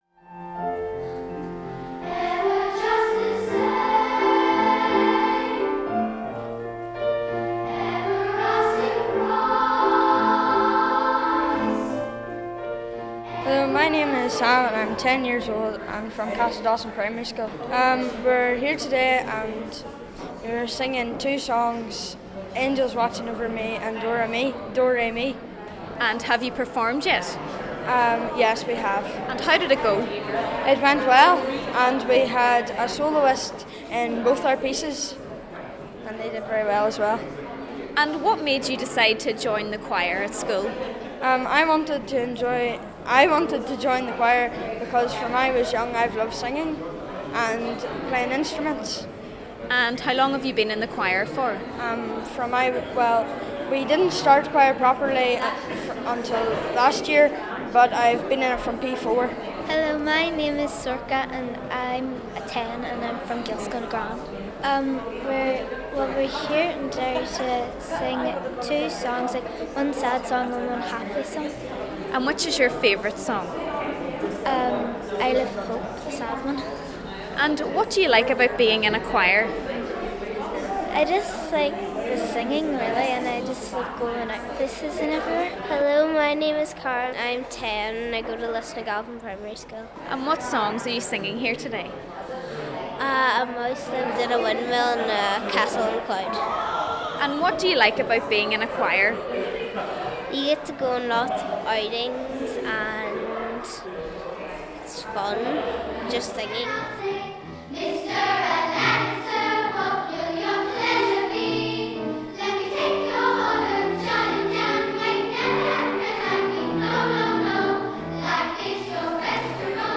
The city was filled with song this morning as the Derry International Choral Festival got into full swing. Schools from across Northern Ireland took part in a number of competitions.